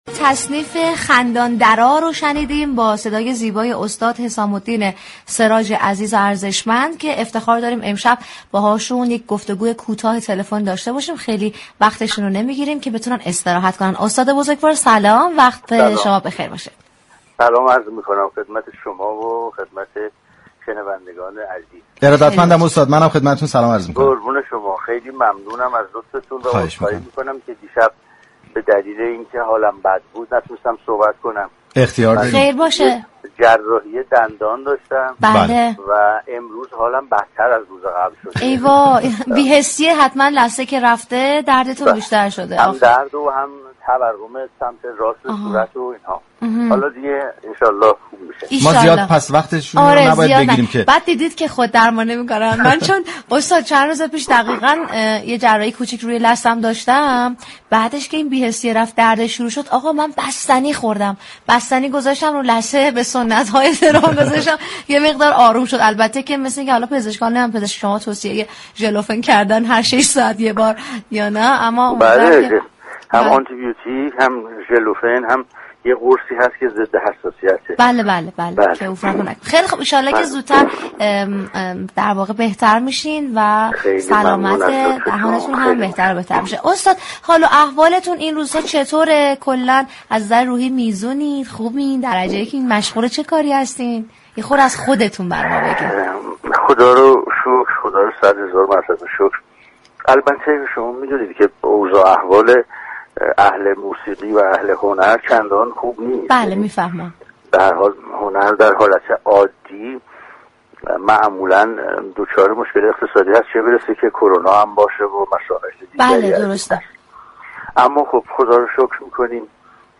به گزارش روابط عمومی رادیو صبا ، "مثبت صباهنگ " جنگ عصرگاهی بر پایه موسیقی و گفتگوی صمیمی در رادیو صبا است، كه با محوریت پخش ترانه وموسیقی های شاد راهی آنتن صبا می شود .
این برنامه روز جمعه 5 میزبان تلفنی حسام الدین سراج خواننده موسیقی های سنتی ایرانی و نوازندهٔ خوب كشورمان شد .